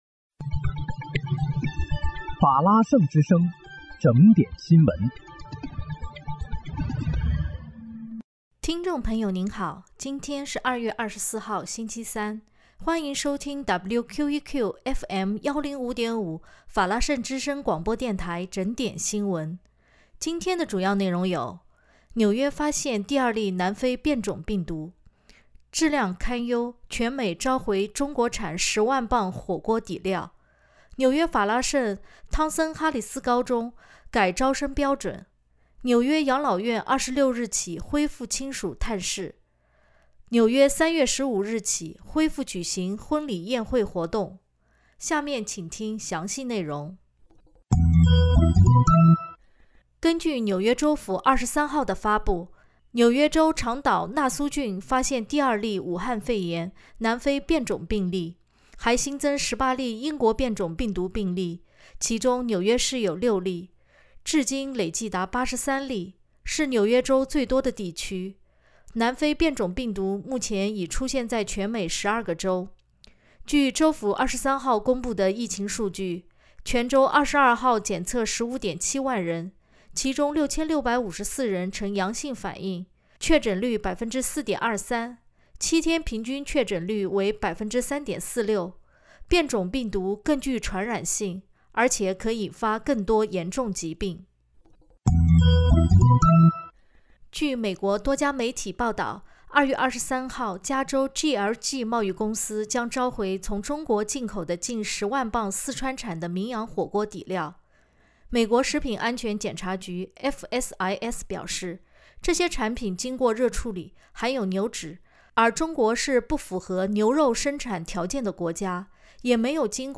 2月24日(星期三）纽约整点新闻